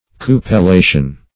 Cupellation \Cu`pel*la"tion\ (k[=u]`p[e^]l*l[=a]"sh[u^]n) n.